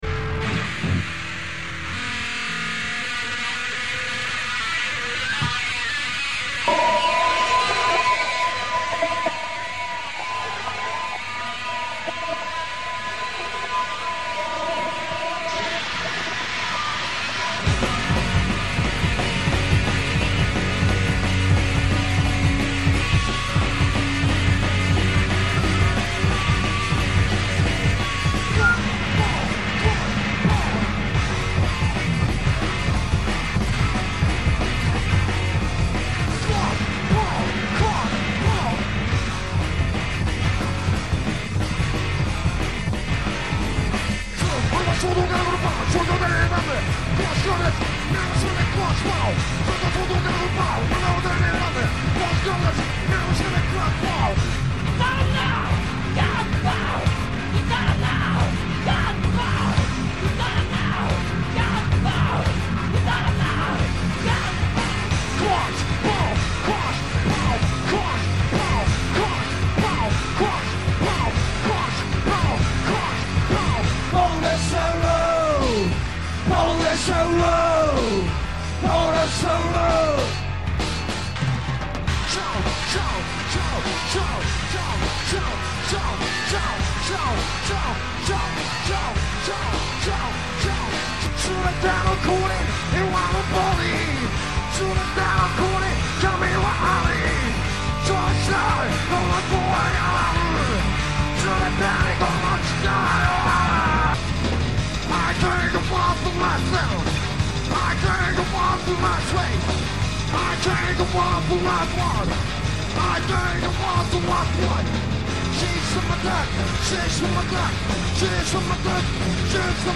Live in Los Angeles